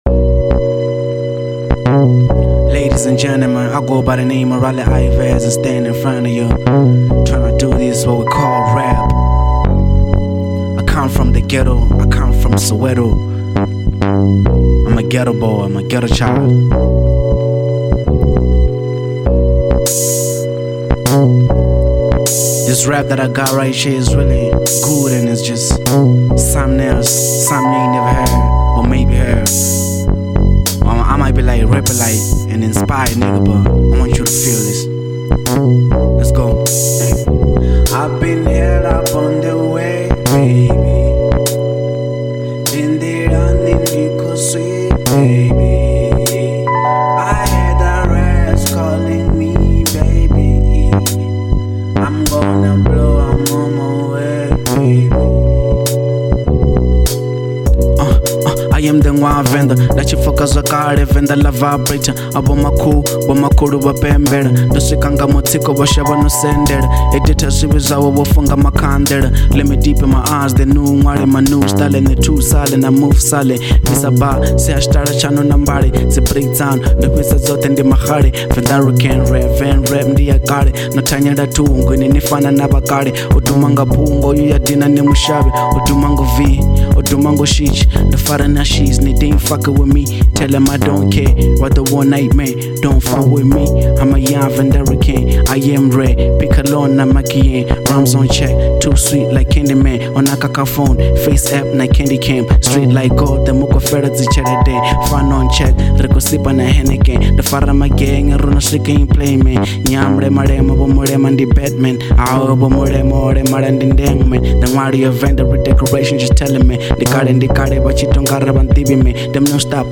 03:31 Genre : Venrap Size